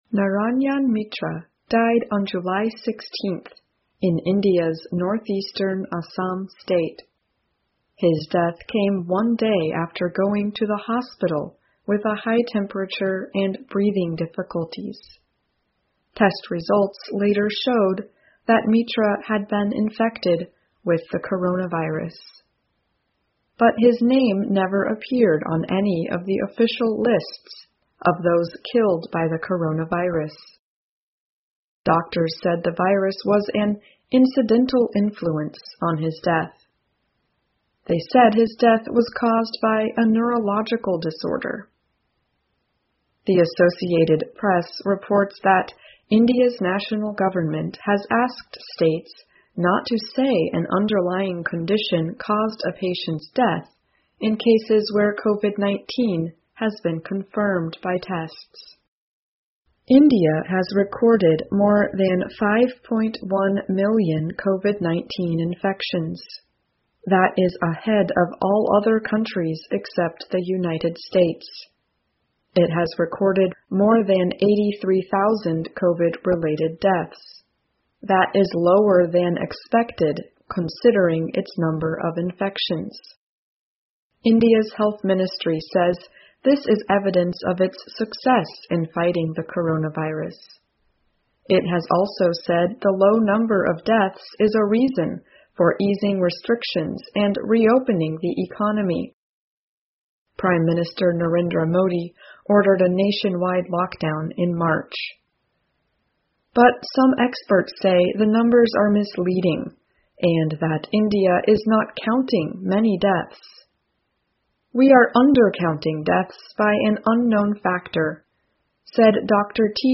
VOA慢速英语--印度是否低估了其新冠的死亡人数？